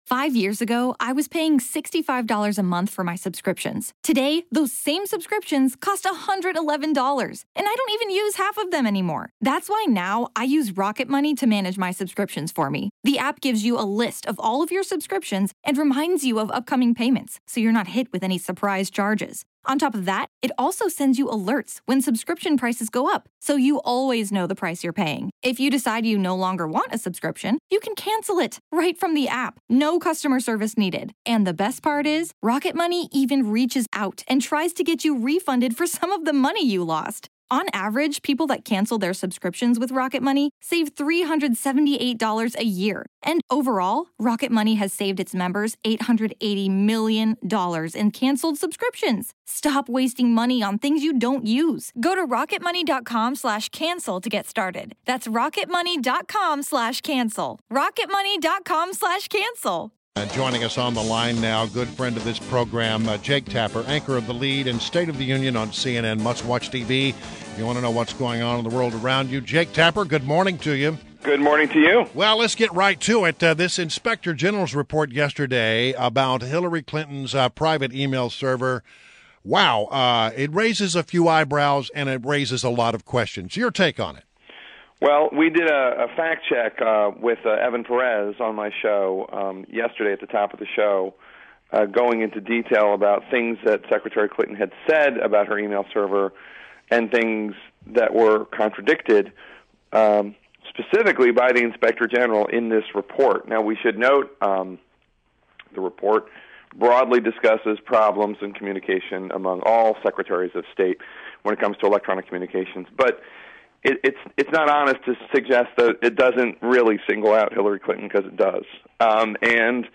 WMAL Interview - CNN's JAKE TAPPER 05.26.16